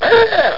HUPE.mp3